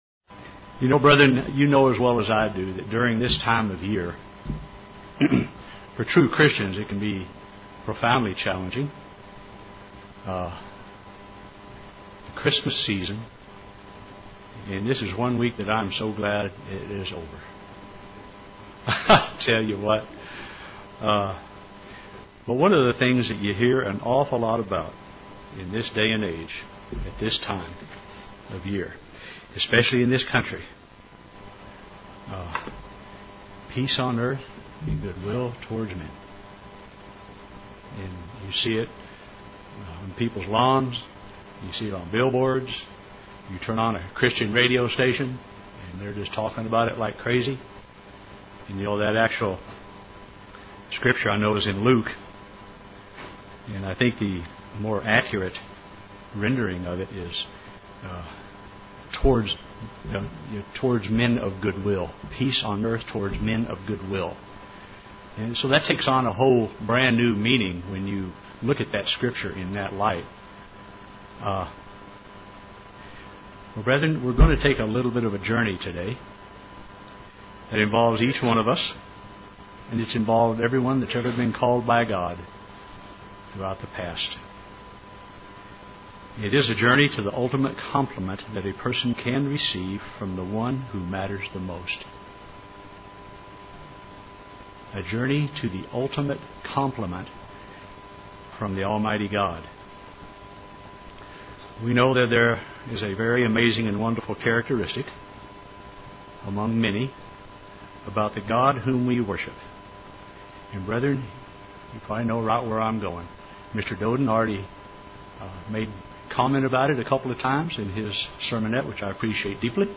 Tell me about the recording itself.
Given in Oklahoma City, OK